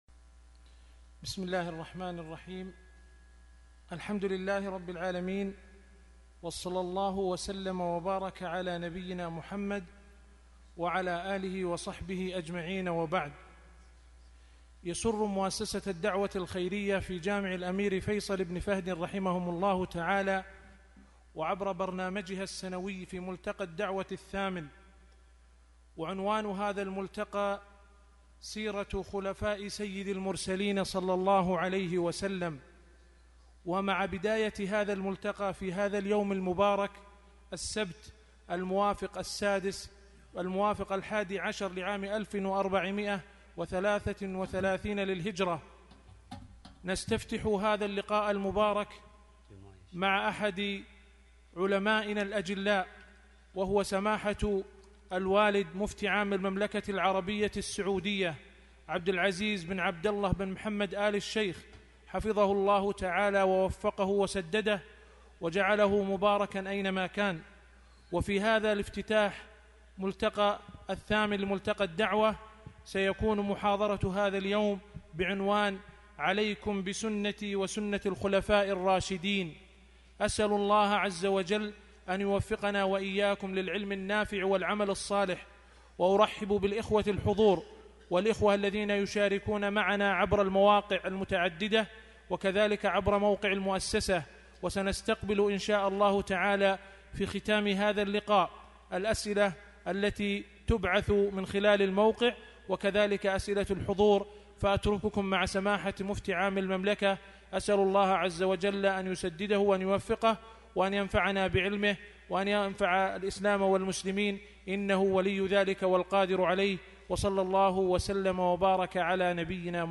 شبكة المعرفة الإسلامية | الدروس | عليكم بسنتي وسنة الخلفاء الراشدين |عبد العزيز آل الشيخ